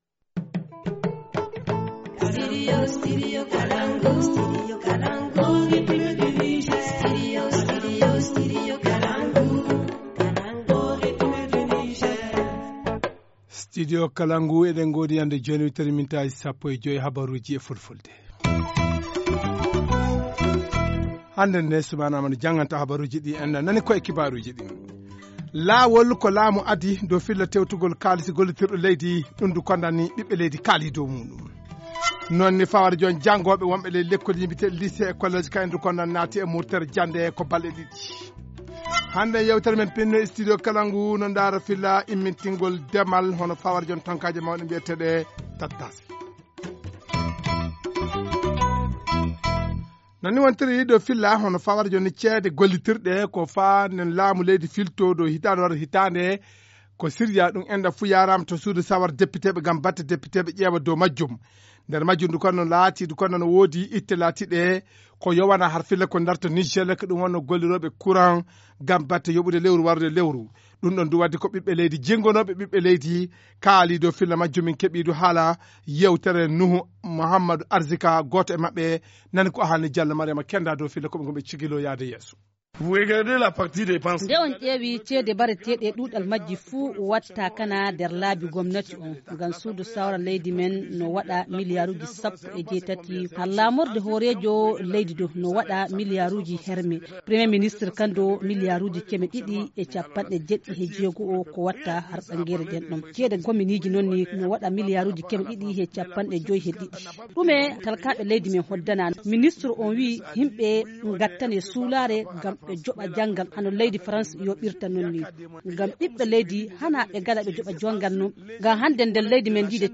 2. Grève de 72 heures de la section Lycée et Collège de la région de Niamey. Les réactions du gouverneur de Niamey et élèves.
Journal en français